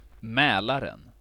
Mälaren (UK: /ˈmɛlərɛn/ MEL-ər-en, US: /ˈmlɑːrən/ MAY-lar-ən,[1][2][3] Swedish: [ˈmɛ̂ːlarɛn]